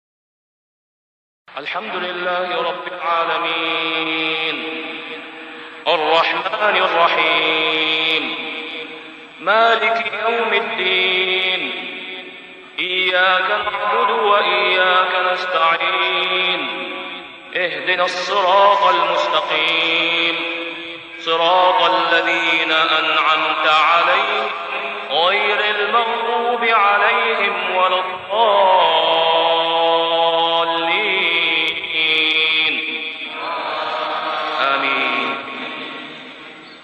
سورة الفاتحة > السور المكتملة للشيخ أسامة خياط من الحرم المكي 🕋 > السور المكتملة 🕋 > المزيد - تلاوات الحرمين